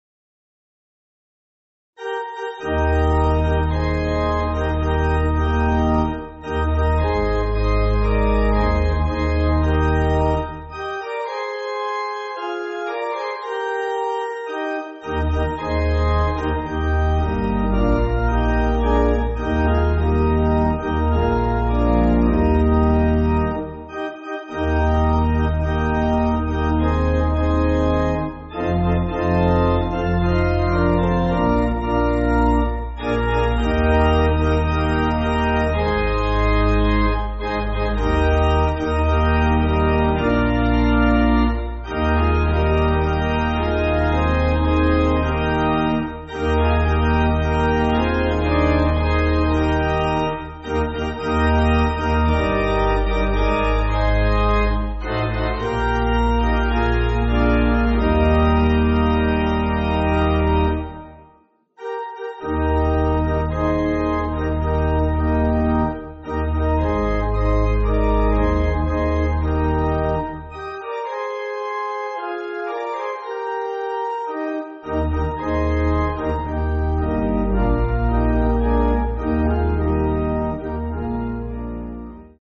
(CM)   3/Eb